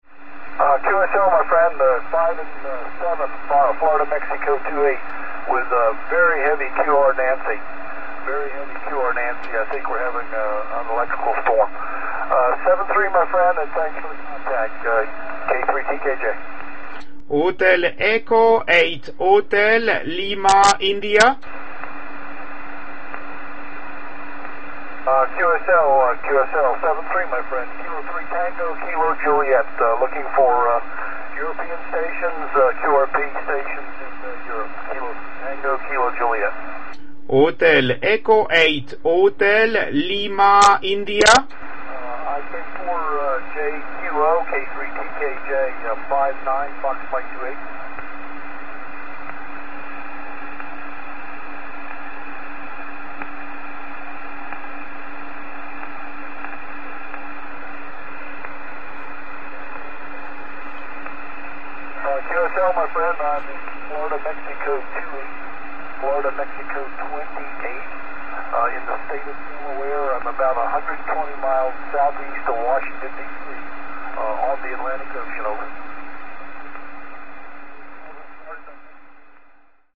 Une autre station qui arrivait bien mais malheureusement pas contactée en raison d’un orage qui semble-t-il le perturbait trop.